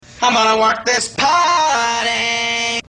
autotune